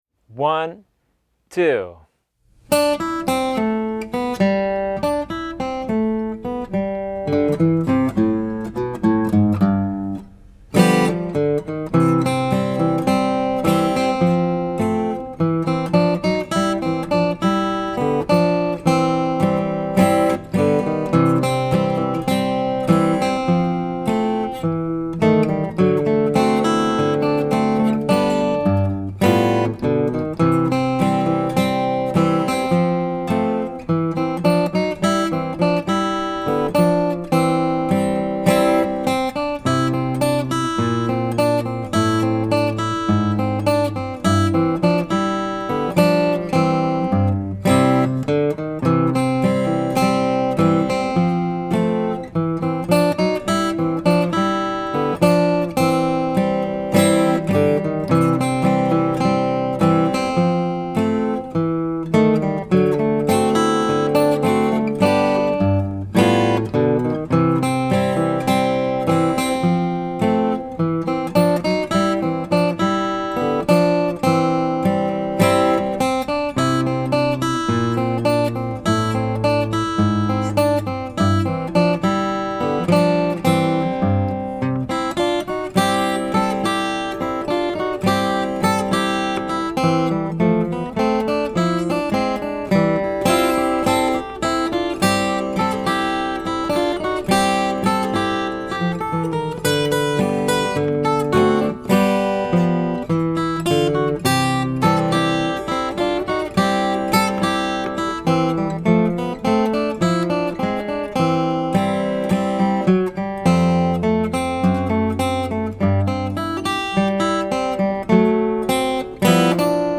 Voicing: Guitar Method